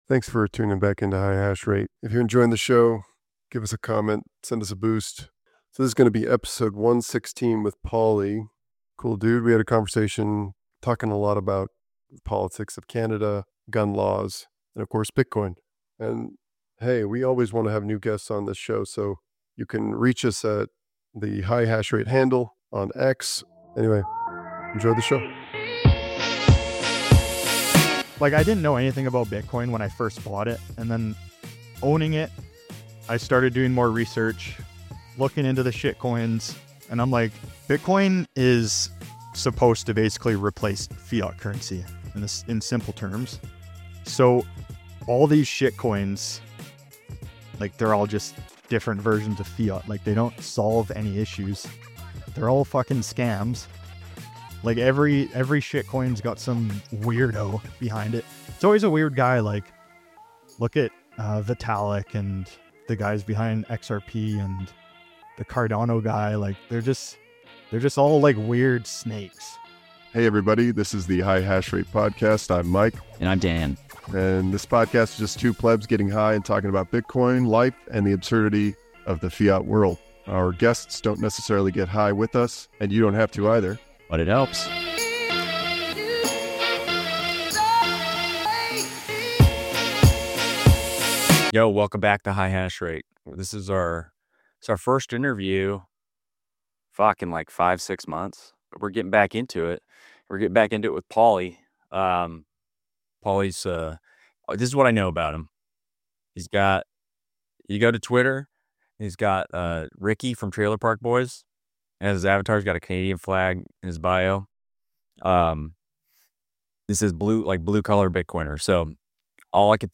High level conversations with Bitcoiners about Bitcoin and how it changes our perspective of reality.